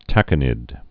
(tăkə-nĭd)